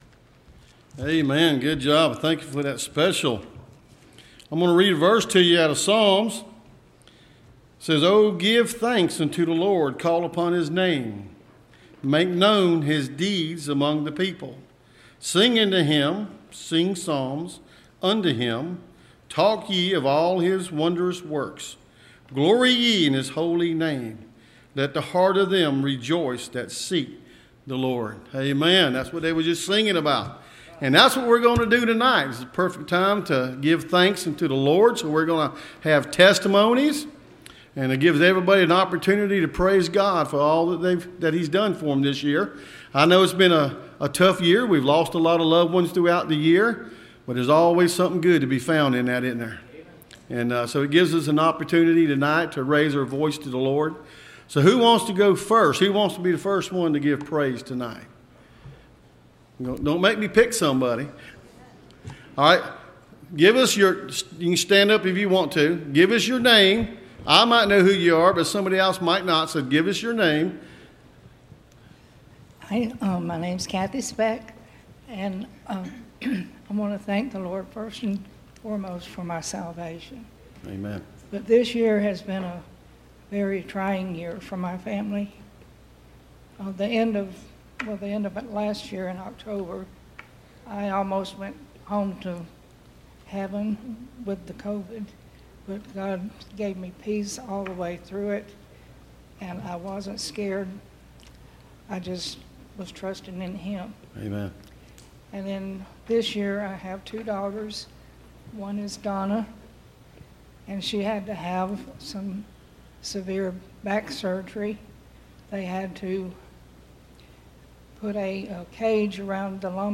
Church Testamonies of Thankfulness – Landmark Baptist Church
Service Type: Wednesday Church